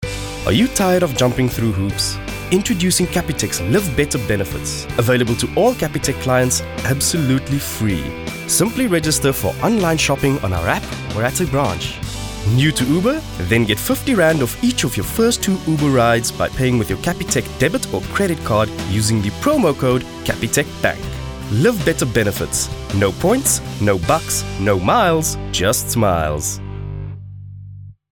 Vocal Styles:
balanced, neutral, straightforward, unaccented
Vocal Age:
My demo reels